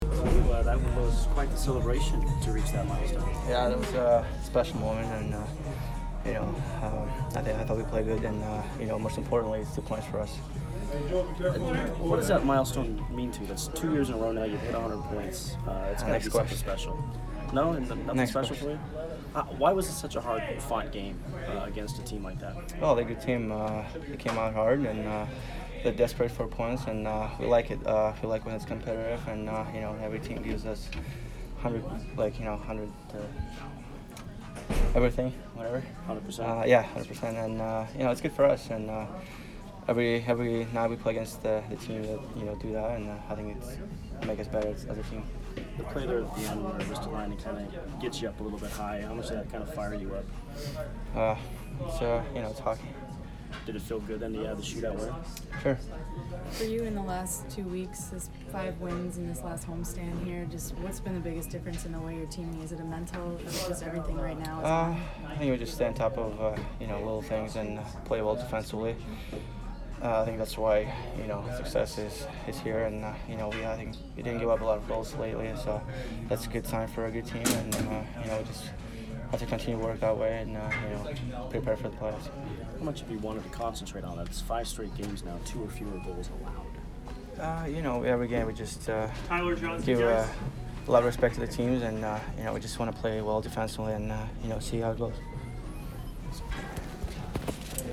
Nikita Kucherov post-game 2/21